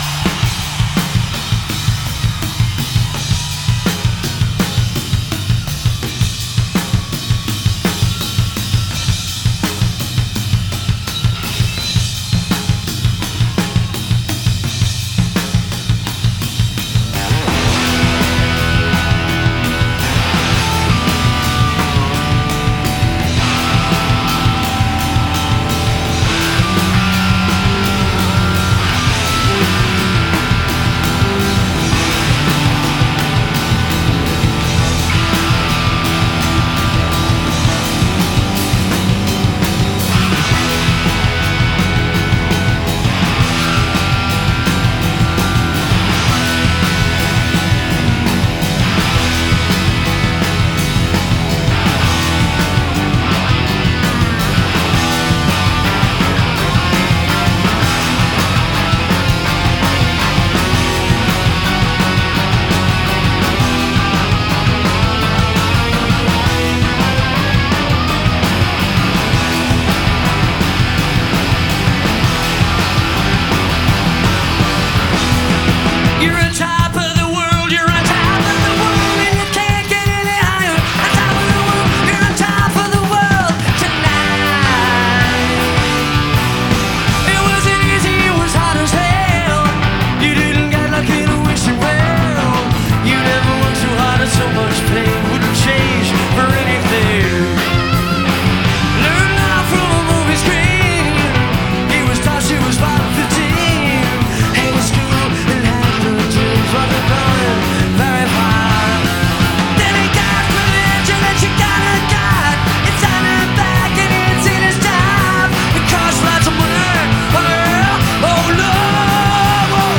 Live at the Forum, Los Angeles, CA - December 1979